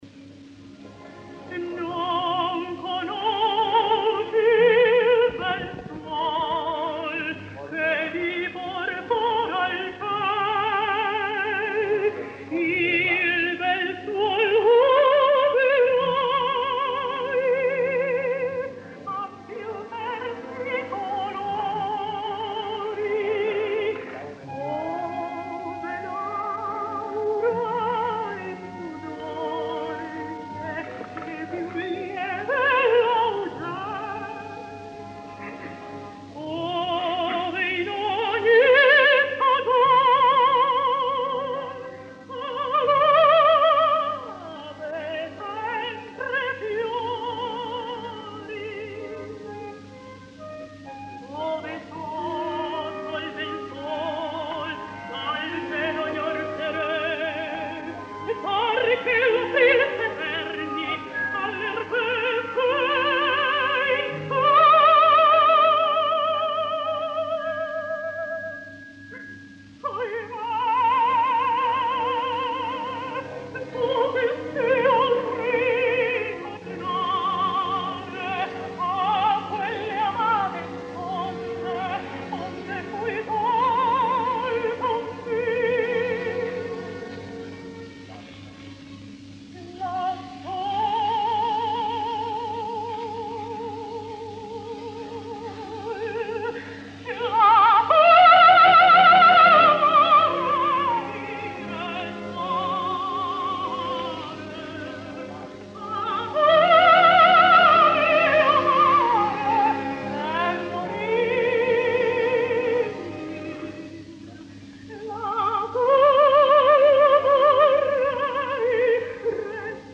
Podem escoltar ara l’ària de la Mignon de Thomas (cantada en italià) “Non conosci suol”, tal com la va cantar al Bellas Artes de Mèxic el dia 28 de juny de 1949.